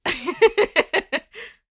female_giggle1.ogg